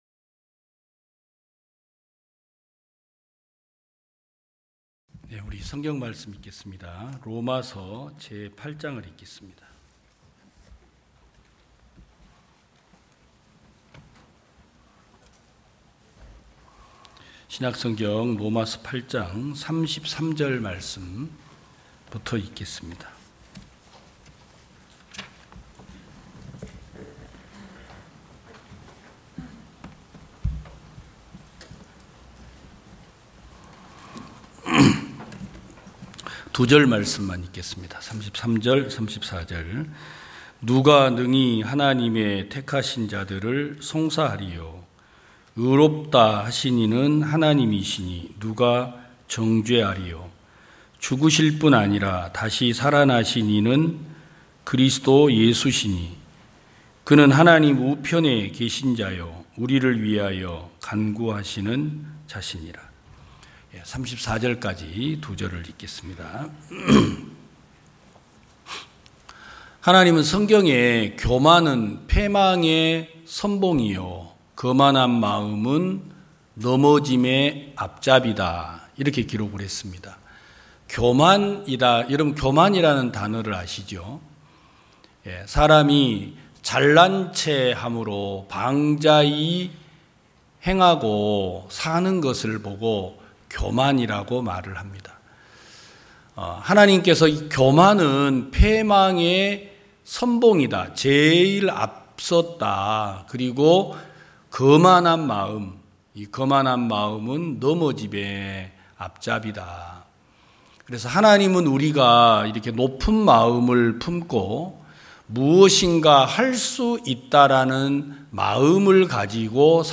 2022년 08월 14일 기쁜소식부산대연교회 주일오전예배
성도들이 모두 교회에 모여 말씀을 듣는 주일 예배의 설교는, 한 주간 우리 마음을 채웠던 생각을 내려두고 하나님의 말씀으로 가득 채우는 시간입니다.